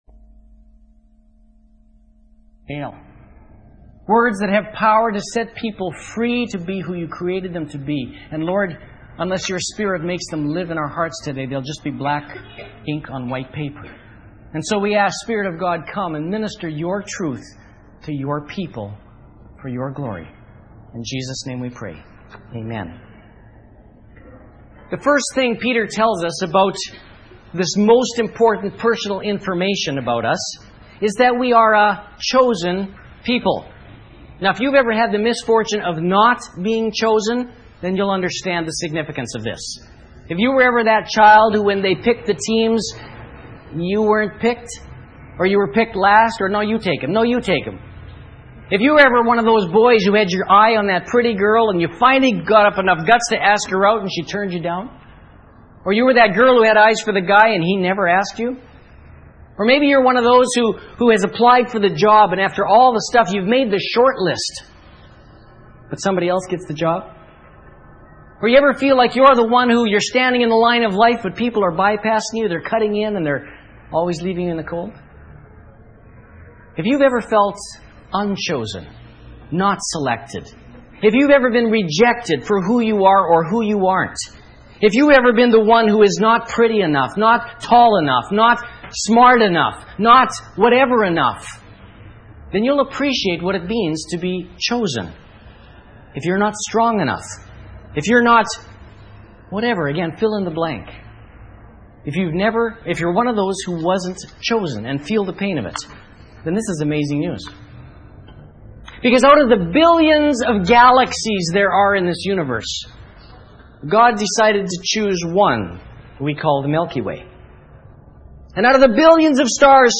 *Note: The exact date for this sermon is unknown.